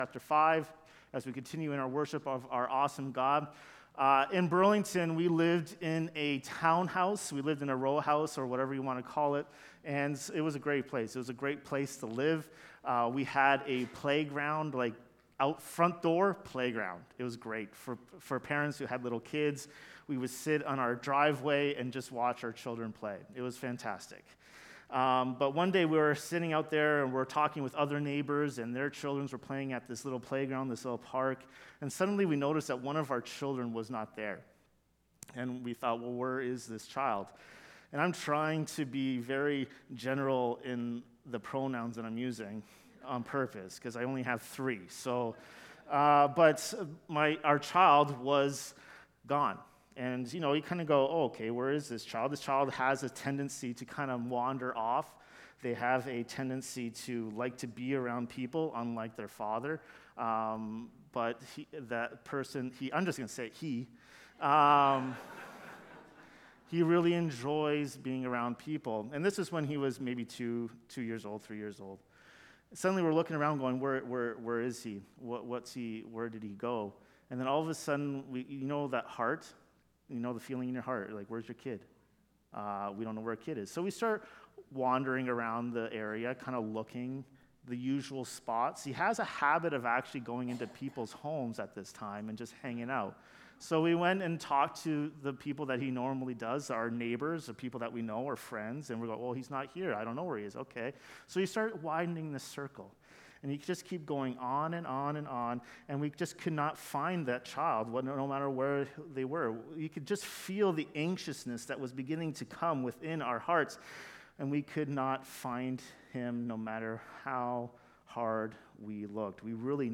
Finding Hope in Suffering – Romans 5:1–5 Sermon